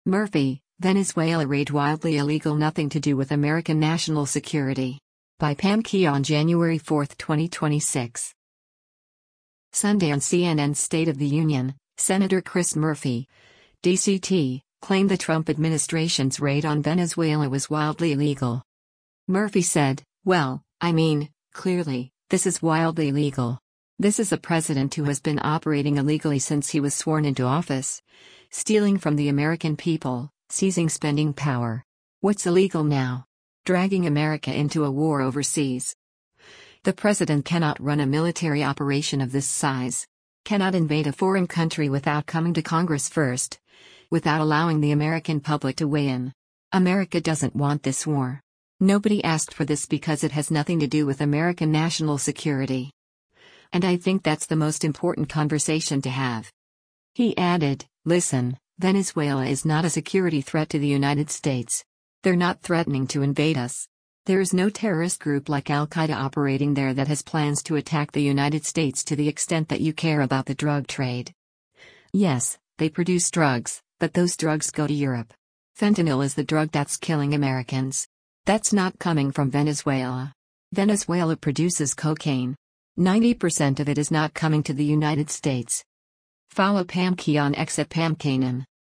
Sunday on CNN’s “State of the Union,” Sen. Chris Murphy (D-CT) claimed the Trump administration’s raid on Venezuela was “wildly illegal.”